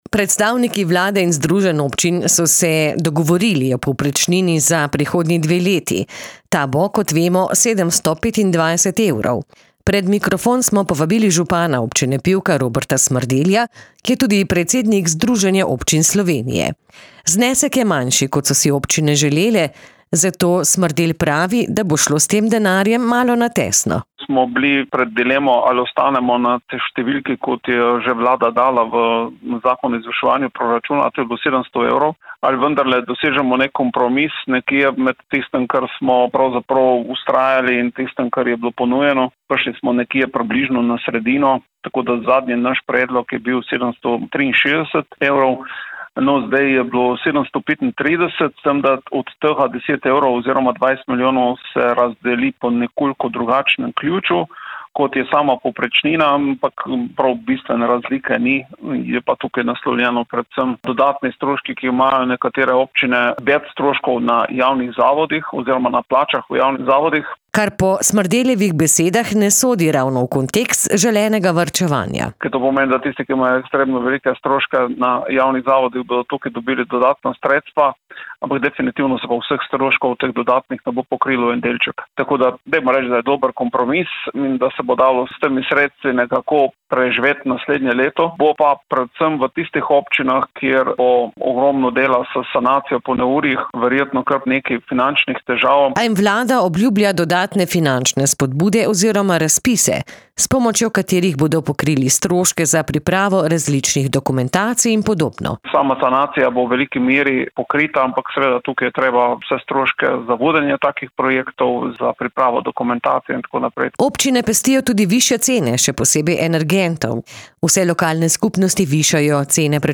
Predstavniki vlade in združenj občin  so se konec oktobra dogovorili o povprečnini za prihodnji dve leti, ta bo 725 evrov. Pred mikrofon smo povabili župana Občine Pivka Roberta Smrdelja, ki je tudi predsednik Združenja občin Slovenije.